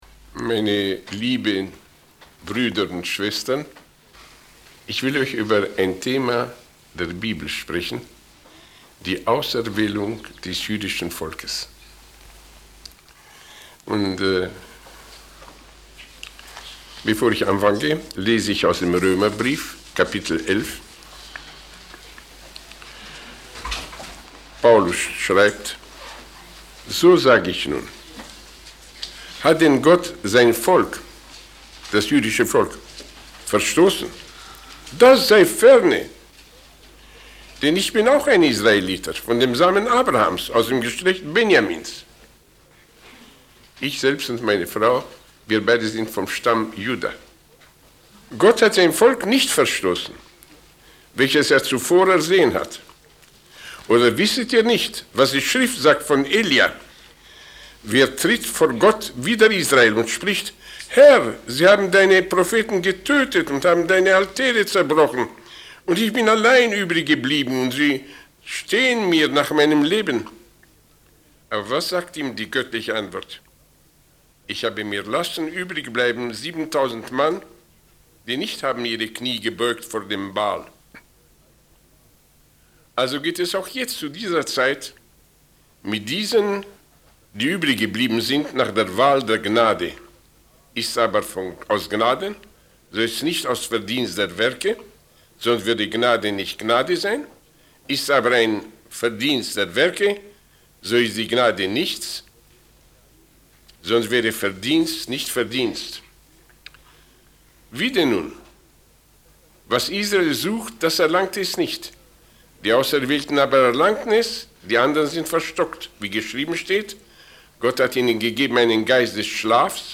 In this sermon, the speaker shares a story about three individuals who encountered a mysterious fountain.